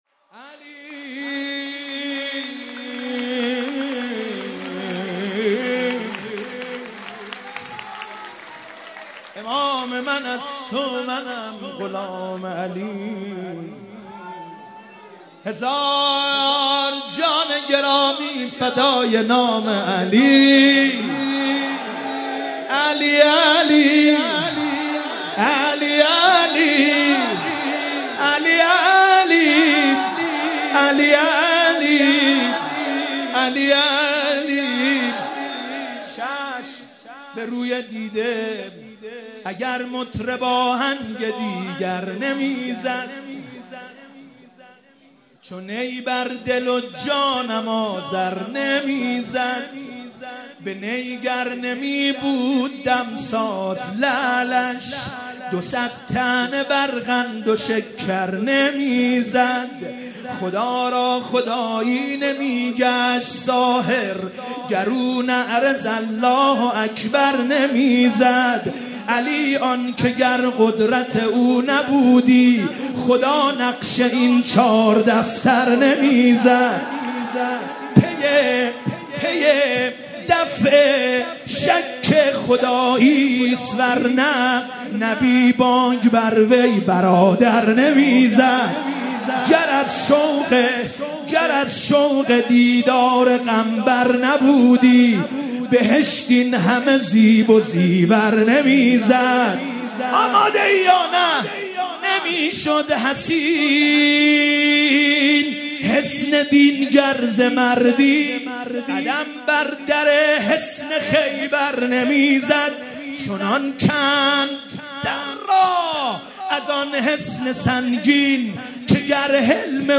Milad_Emam_Zaman_1396_d.mp3